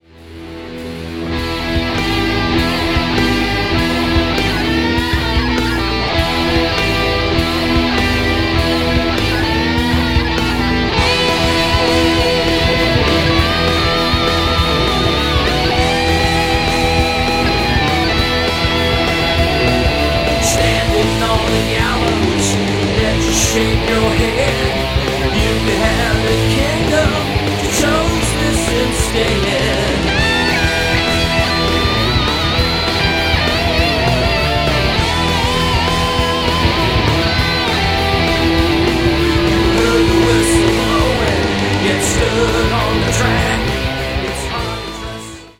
Recorded at:  Various home studios in the USA,